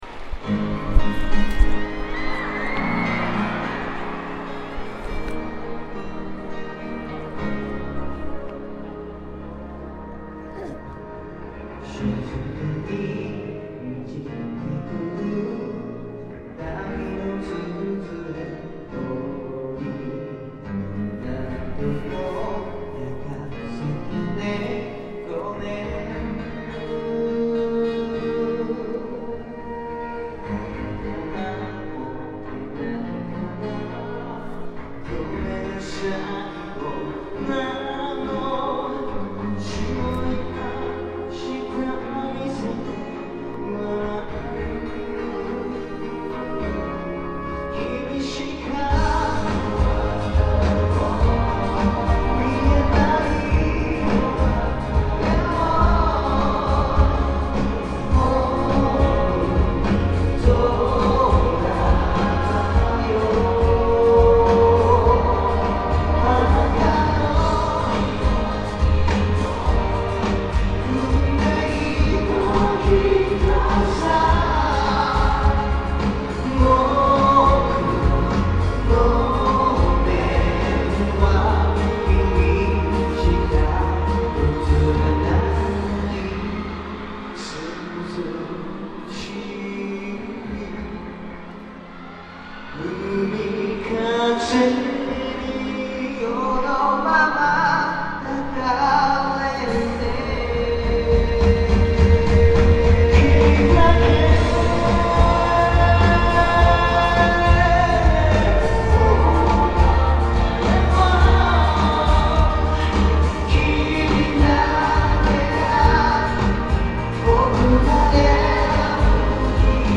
고라응요~~~♪ 요코니와~~~♪뭔가 사사삭 가볍게 두드려주는 듯한 발음ㅠㅠㅠ
엄밀히 말해서는 카운트다운 끝나고 나서 부르는 첫곡 이었기 때문에 날짜는 (09/1/1)이에요ㅋㅋ